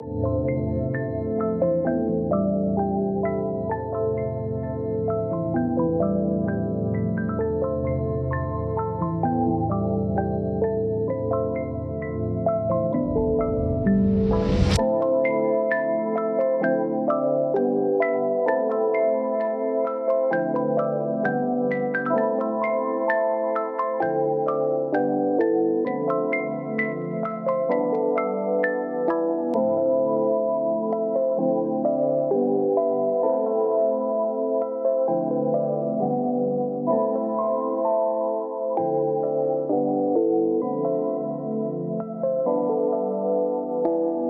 • High-Quality RnB Samples 💯